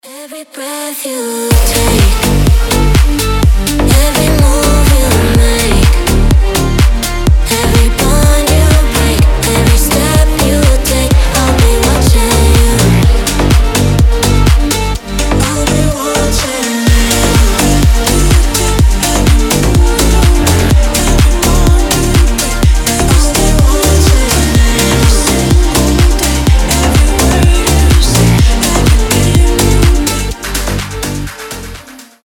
• Качество: 320, Stereo
deep house
retromix
женский голос
Cover
progressive house
Vocal House